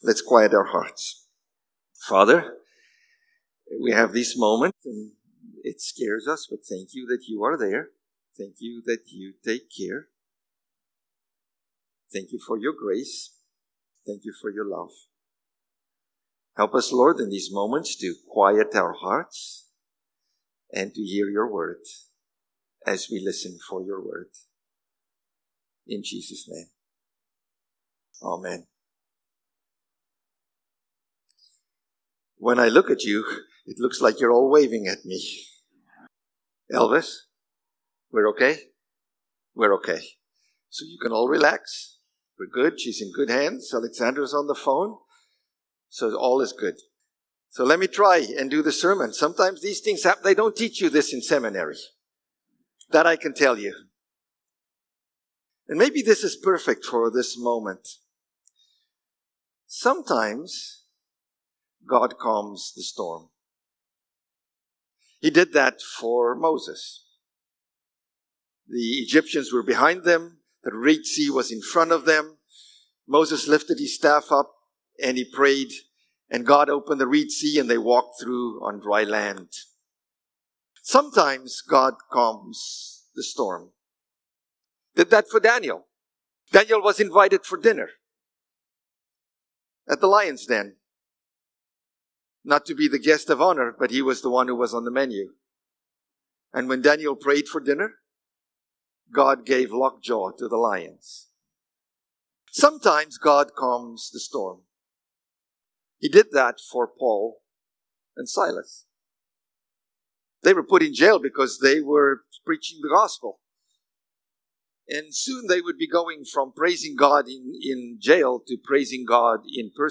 June-22-Sermon.mp3